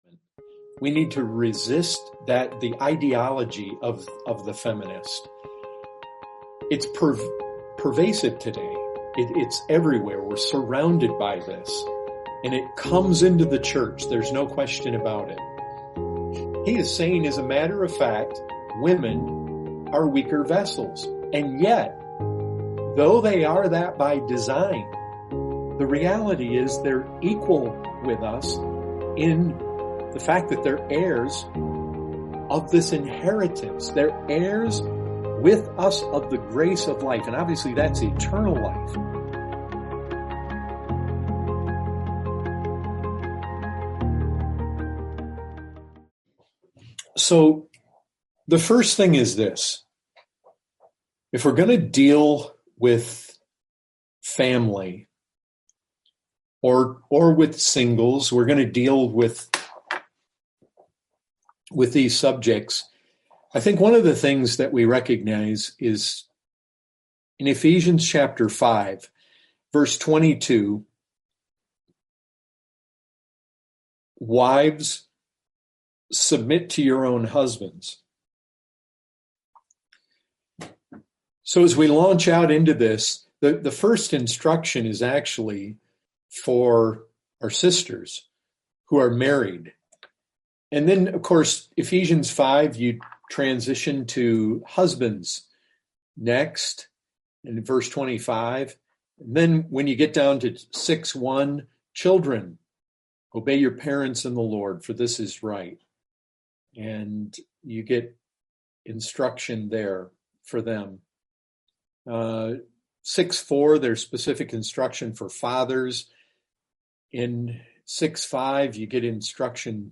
This series was a Bible Study done through Zoom to Grace Fellowship Manchester.